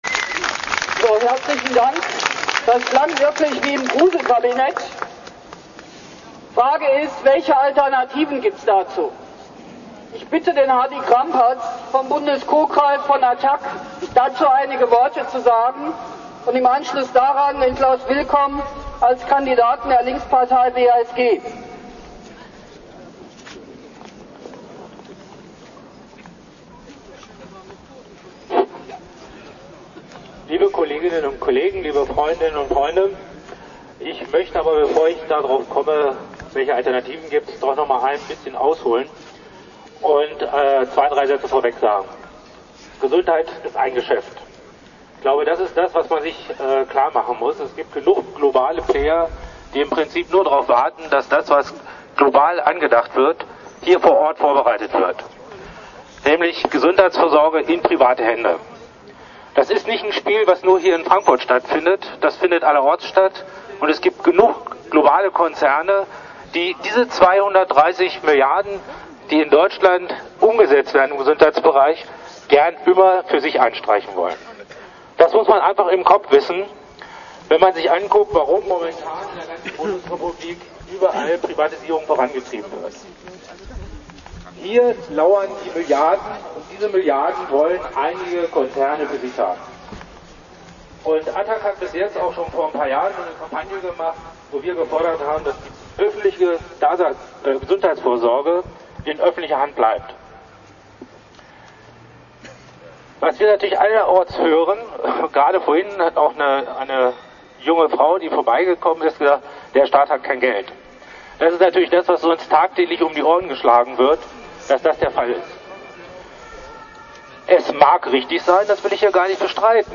Frankfurt, 6. März´06, Info-Veranstaltung